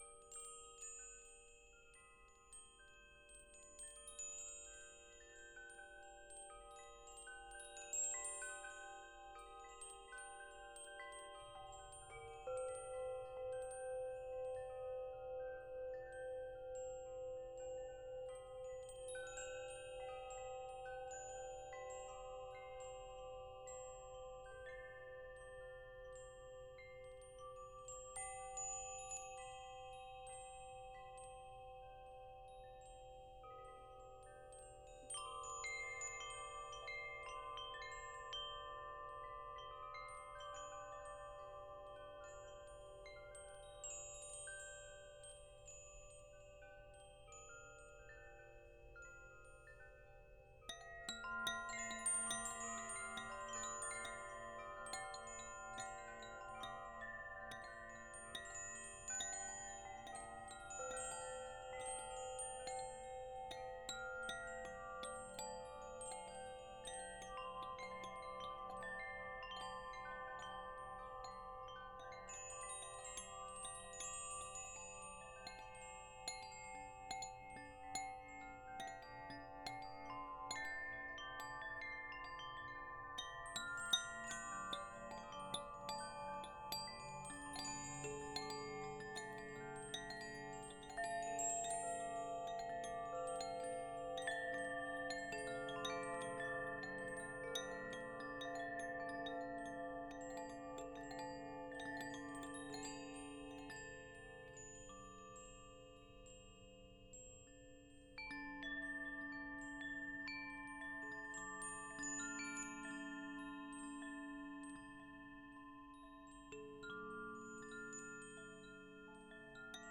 Best Hypnotic Wind Chimes ~ Long Nice Loop Sound Effect — Free Download | Funny Sound Effects
Perfect for ambience, ambient, atmosphere.